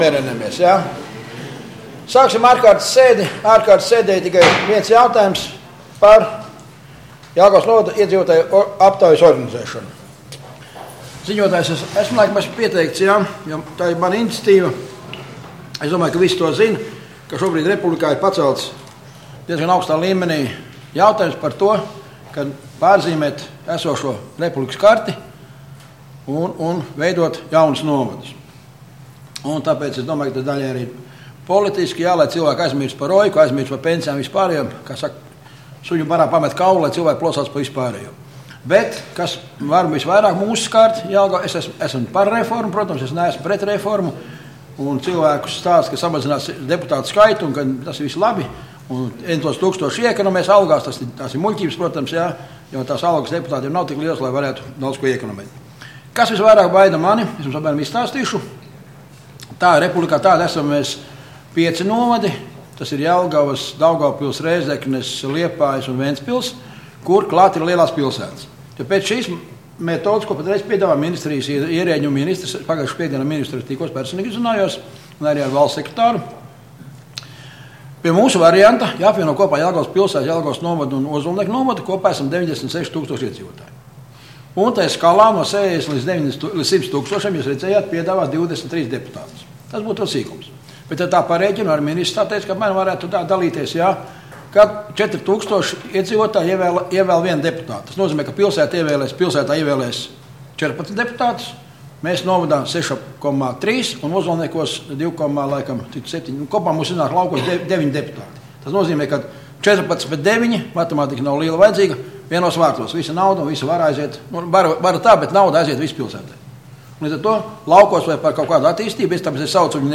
Domes ārkārtas sēde Nr. 5